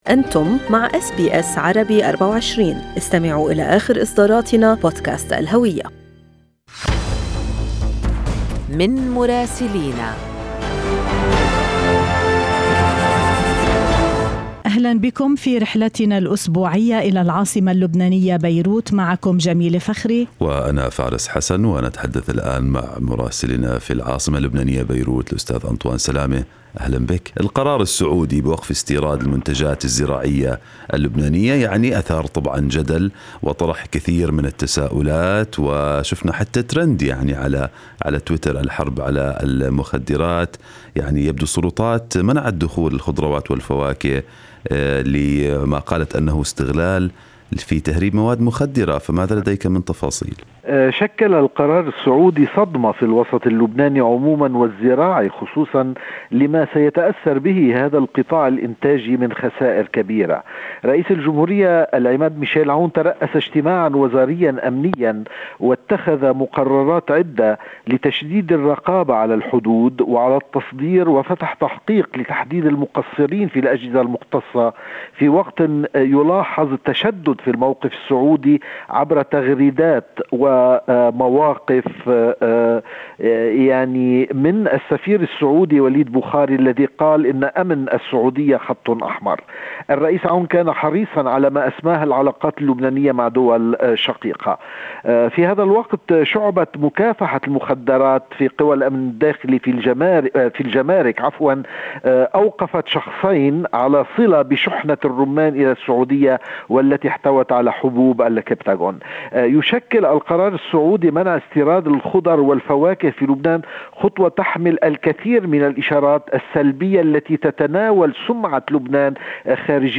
من مراسلينا: أخبار لبنان في أسبوع 27/4/2021